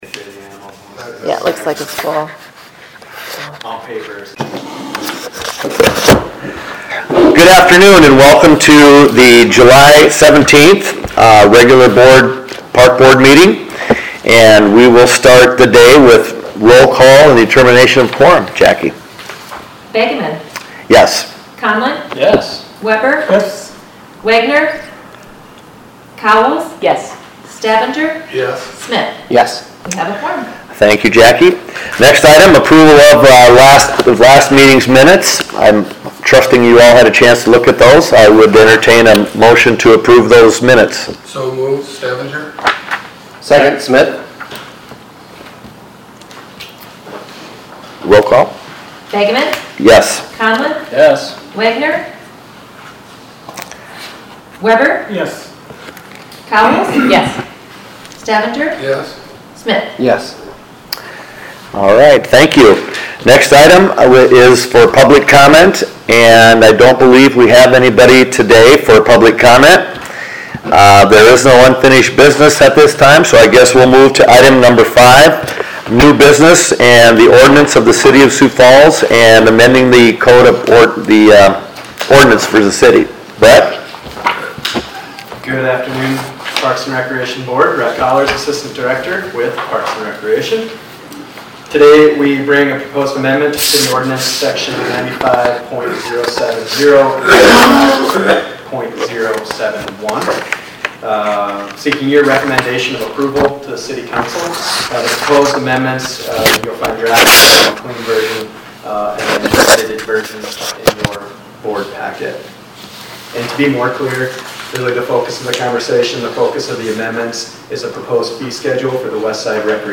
Sioux Falls Parks and Recreation Board Meeting